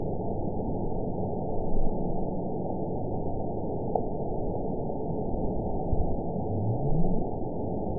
event 921664 date 12/15/24 time 09:22:36 GMT (4 months, 3 weeks ago) score 9.67 location TSS-AB03 detected by nrw target species NRW annotations +NRW Spectrogram: Frequency (kHz) vs. Time (s) audio not available .wav